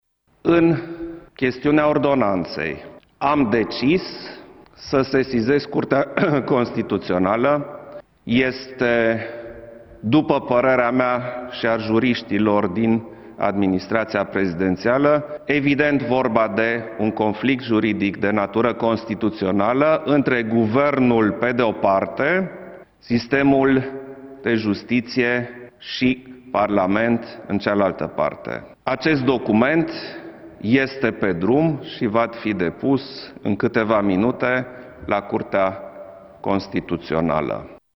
Legat de Ordonața de Urgență a Guvernului prin care au fost modificate Codurile penale, Klaus Iohannis a anunțat că va sesiza Curtea Constituțională a României în cel mai scurt timp: